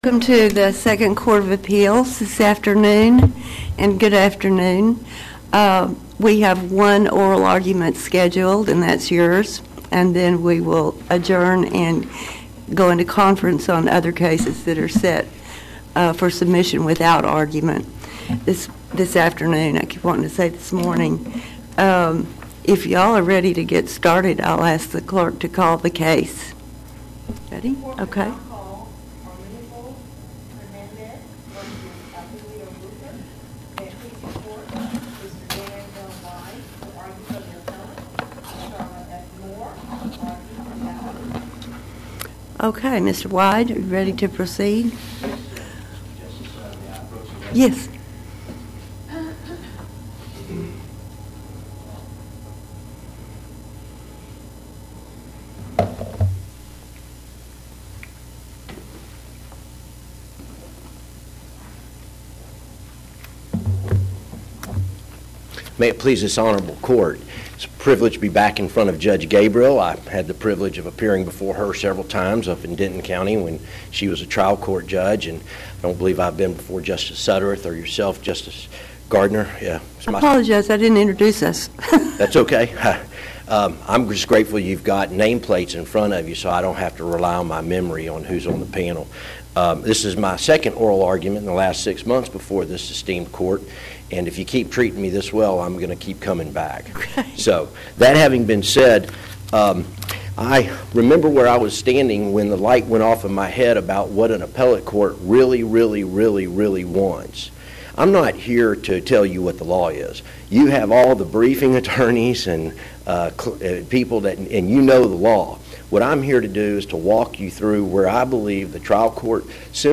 TJB | 2nd COA | Practice Before the Court | Oral Arguments | 2016